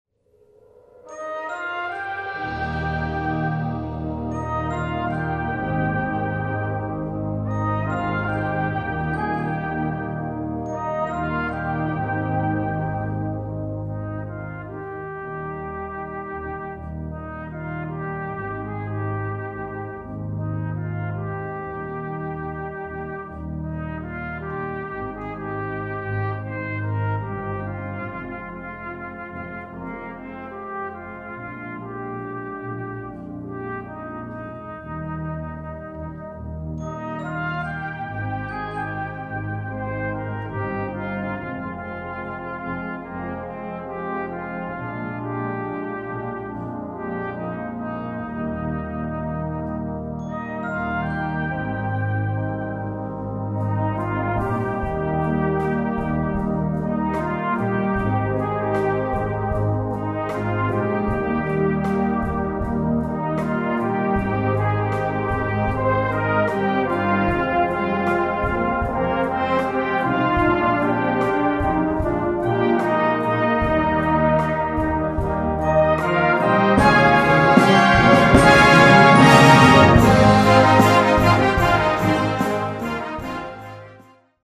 Gattung: Poptitel
A4 Besetzung: Blasorchester Zu hören auf